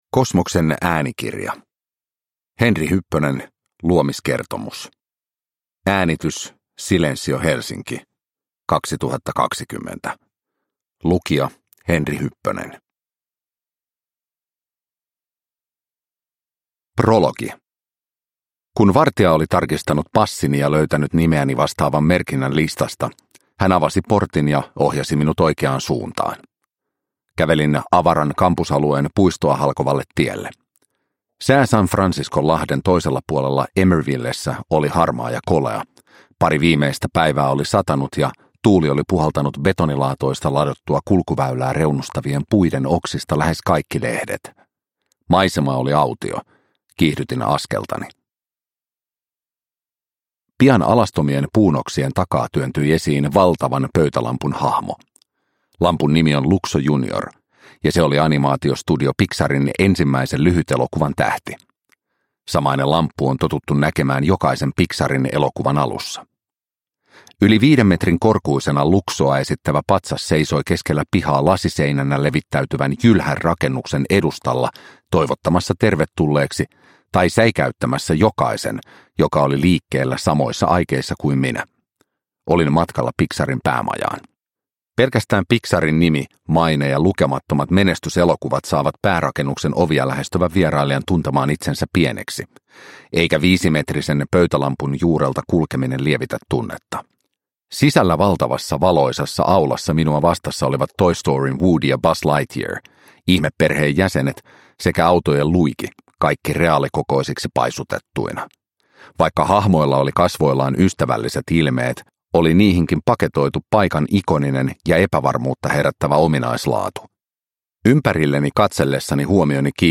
Luomiskertomus – Ljudbok